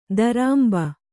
♪ darāmba